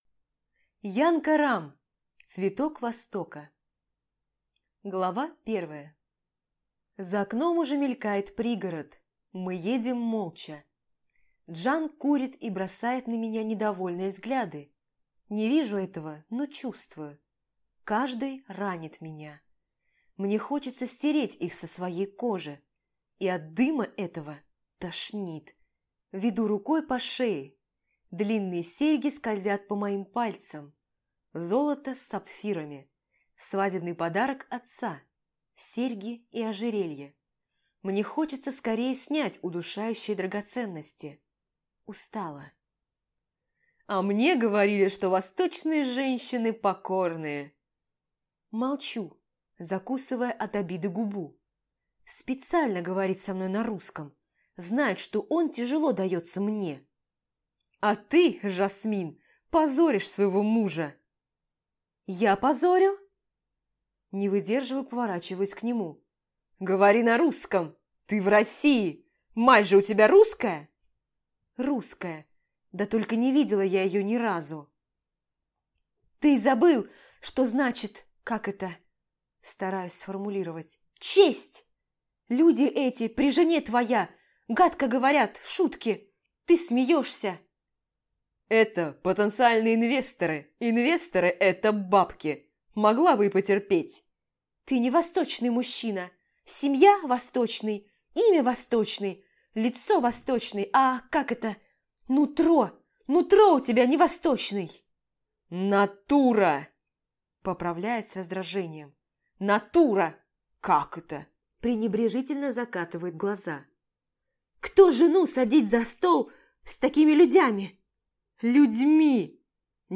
Аудиокнига Цветок Востока | Библиотека аудиокниг